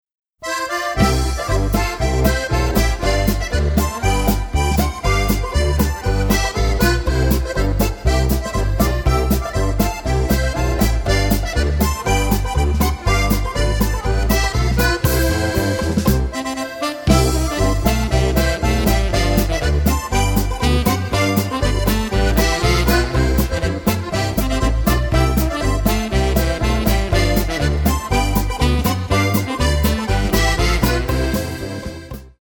button accordion, bass, and piano
saxophone
clarinet
drums
banjo